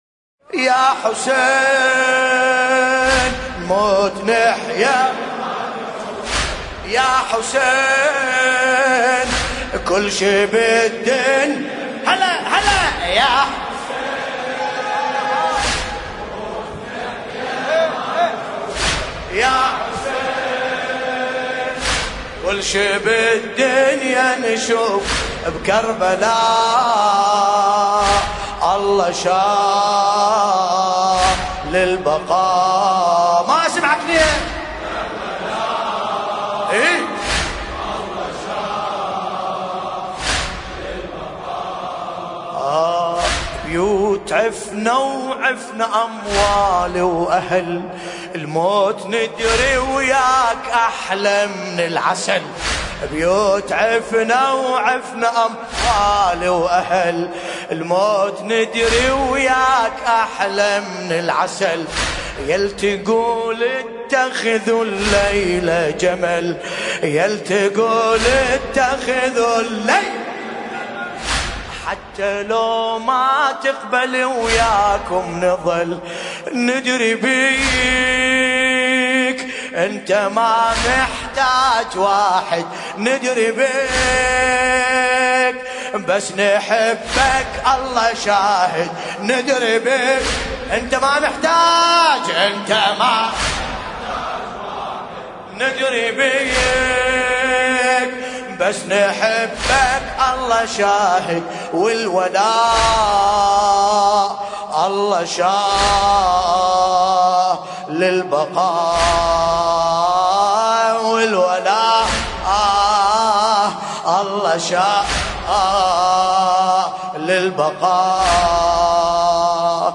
ملف صوتی يا حسين بصوت باسم الكربلائي